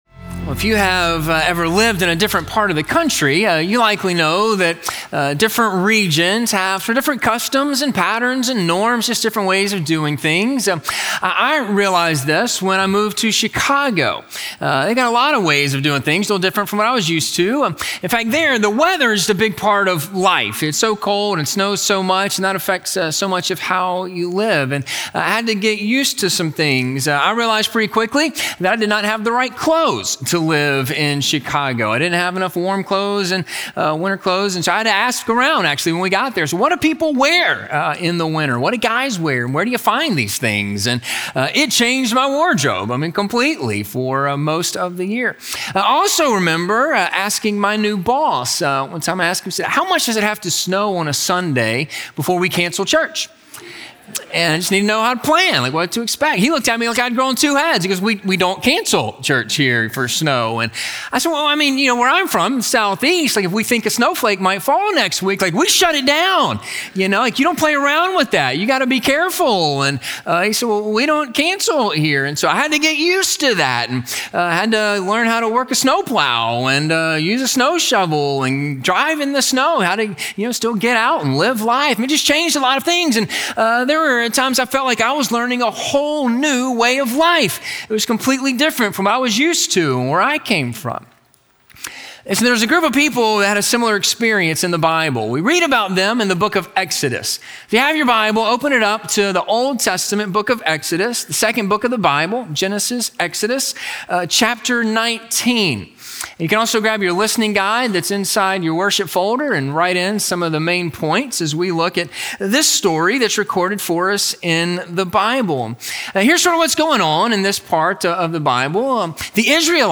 The God Who Commands - Sermon - Ingleside Baptist Church